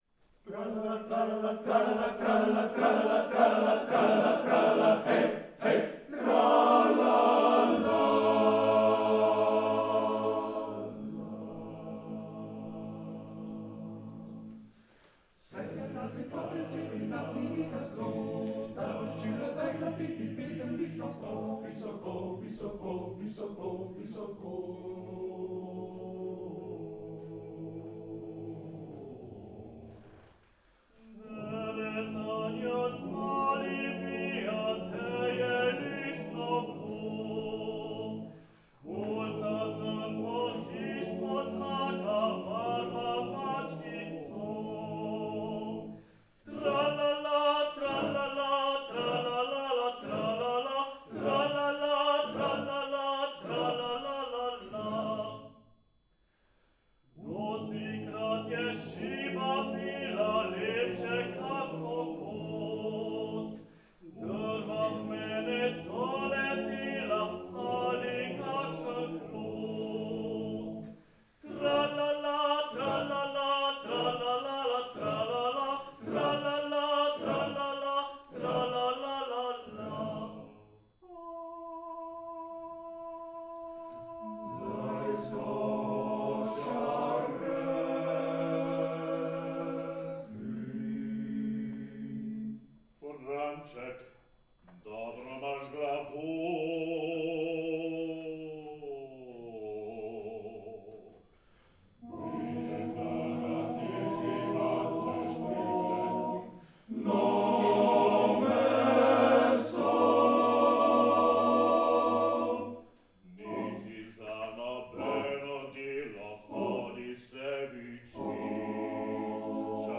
V Tednu ljubiteljske kulture 2018 smo združili svoje moči, oziroma glasove – pevci Komornega moškega pevskega zbora Davorina Jenka in Moškega pevskega zbora Pivka.
Namen je bil dosežen – koncerta sta obogatila tako poslušalce na obeh koncih naše domovine, kot nas pevce.
NAŠ MAČEK (Fantovska
KMoPZ Davorina Jenka Cerklje